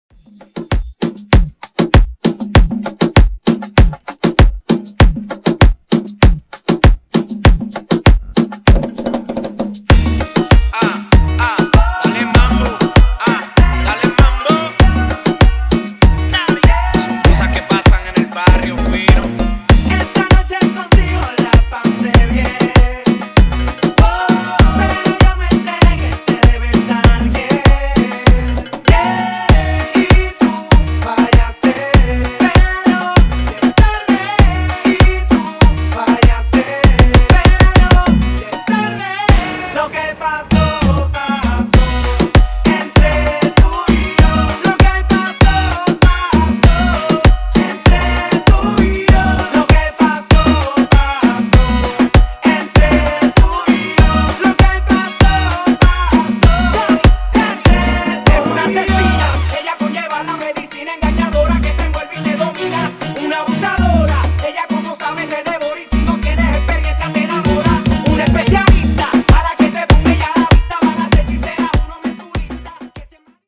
RE-DRUM
Clean BPM: 128 Time